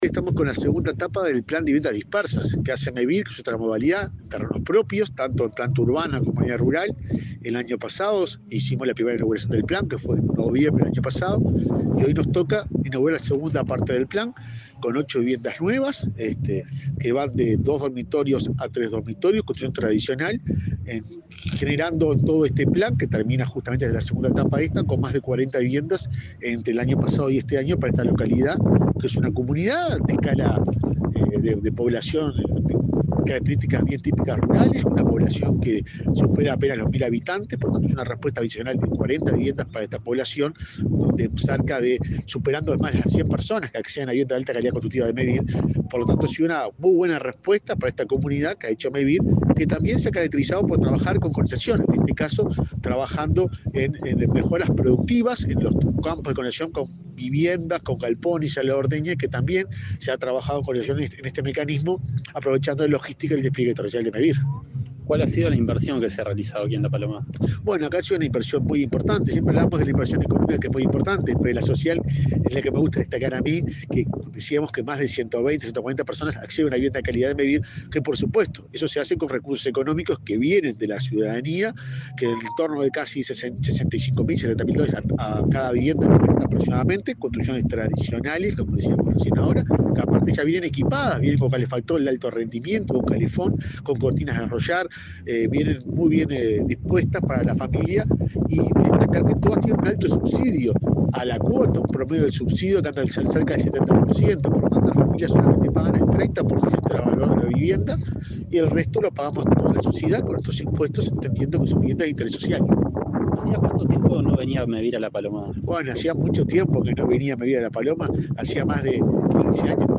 Entrevista al presidente de Mevir, Juan Pablo Delgado